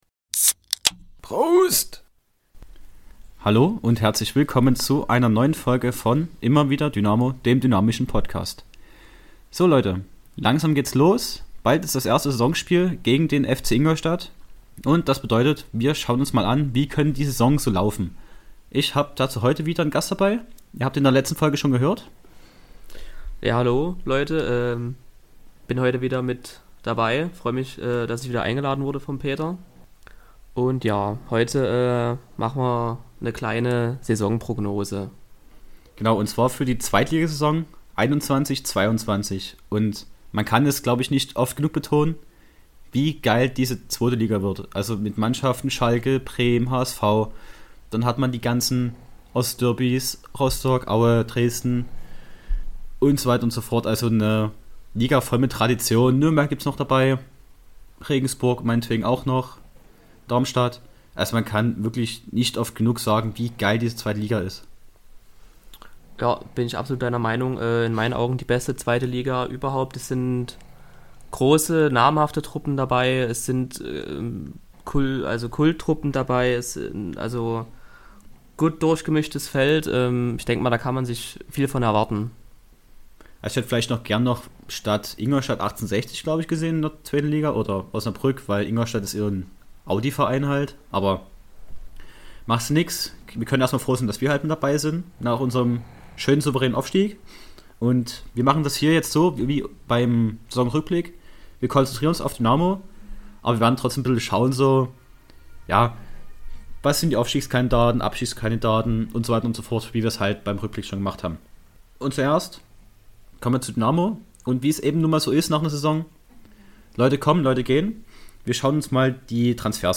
Schon vor Beginn der neuen Saison ist es jetzt schon spannend bei der SGD. Ich schaue mit einem Gast mal an wie die Saison laufen könnte.